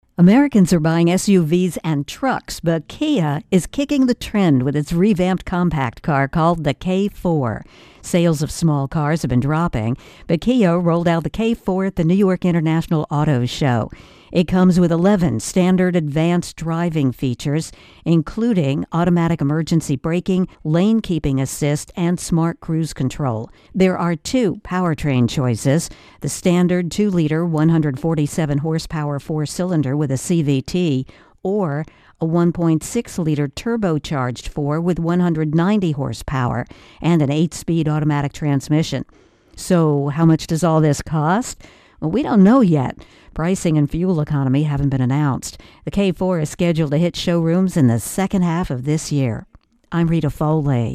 reports on a new compact car.